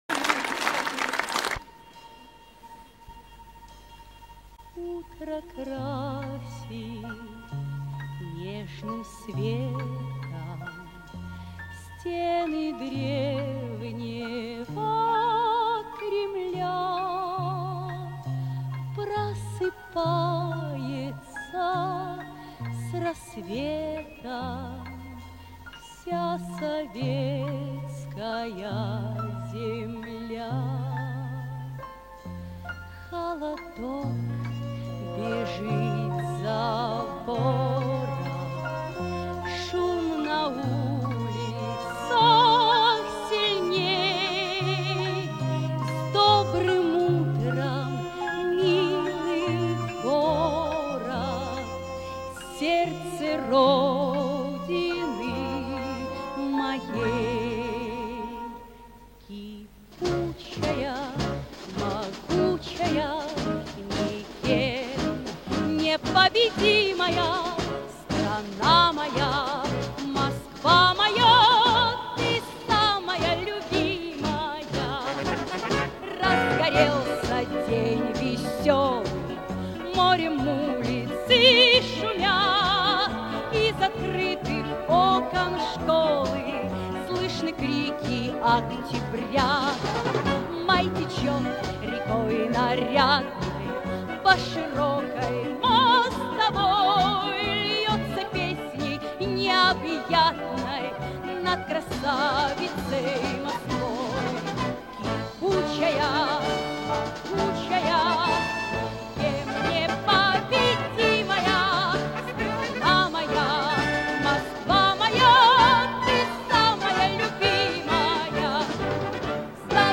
Да нет, это фильм-концерт 1987 г.